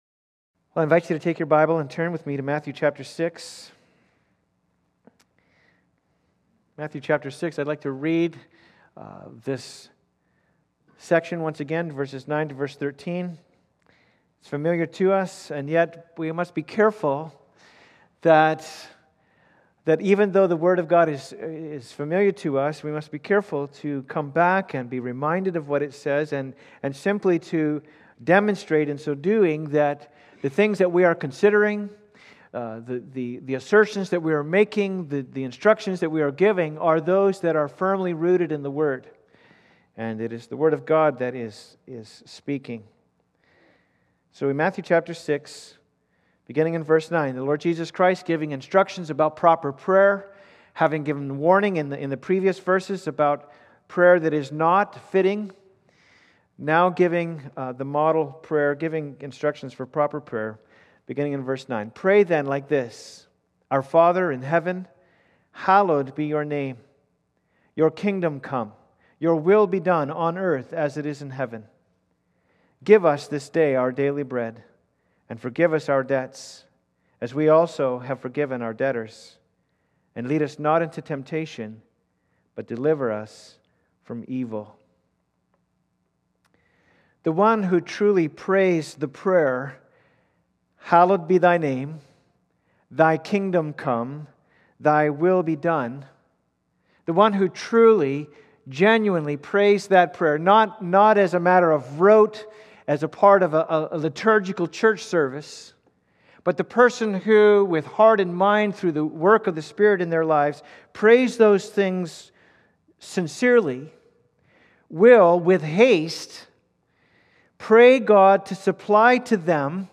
Service Type: Sunday Service
3.15_Sermon.m4a